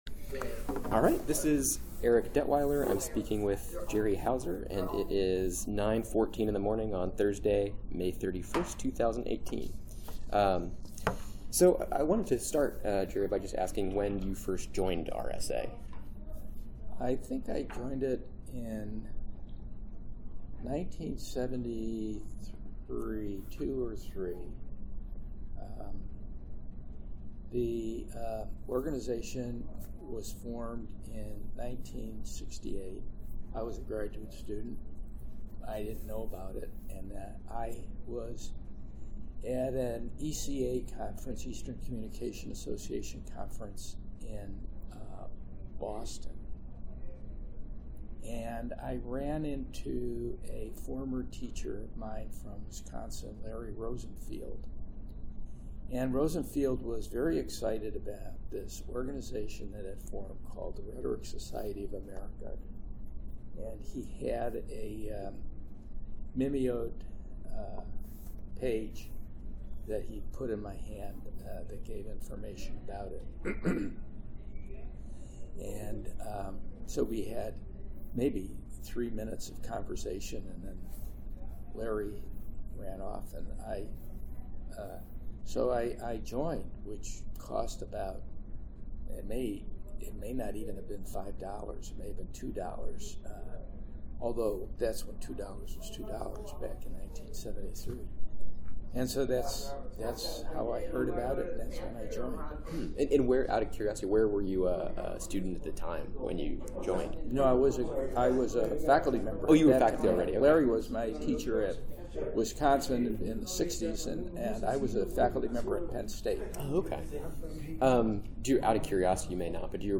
Oral history interview
2018 RSA Conference in Minneapolis, Minnesota